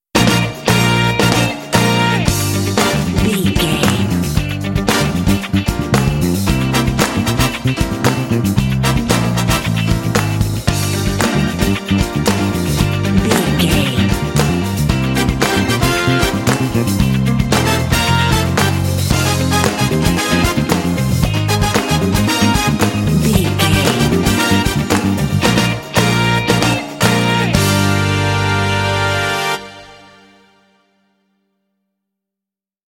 Aeolian/Minor
funky
groovy
bright
lively
energetic
bass guitar
electric guitar
brass
electric organ
piano
drums
percussion
Funk
jazz